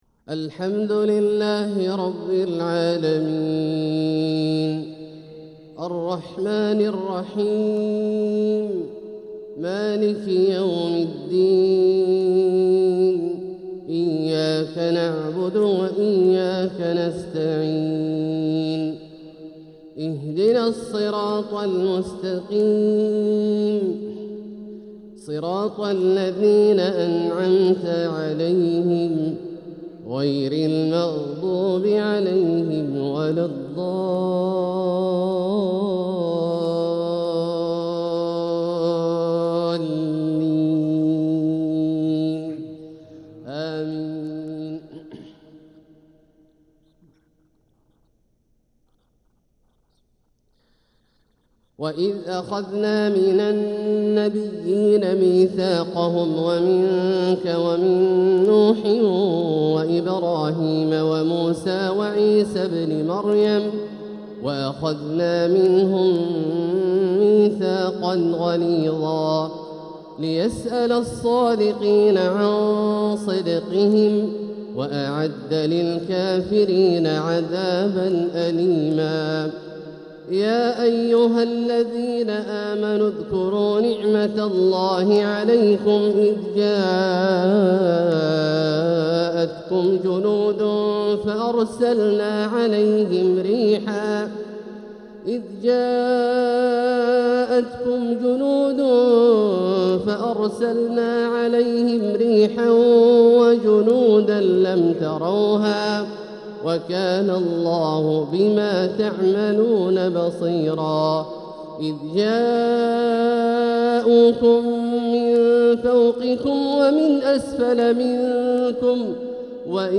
الشيخ عبدالله الجهني | فجرية تذهل الألباب من سورة الأحزاب | 28 ذو الحجة 1446هـ > ١٤٤٦ هـ > الفروض - تلاوات عبدالله الجهني